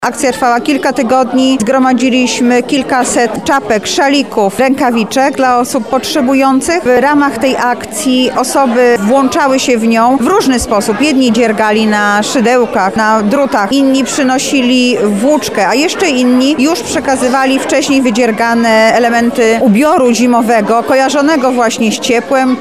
Dziś (5.12) w Trybunale Koronnym miało miejsce podsumowanie akcji „Otuleni Dobrem„.
Dzisiaj przekazujemy te dary jednostkom, które tego potrzebują, ale jednocześnie chcemy podziękować wszystkim, którzy najbardziej zaangażowali się w tę akcję – mówi Anna Augustyniak, Zastępca Prezydenta ds. Społecznych: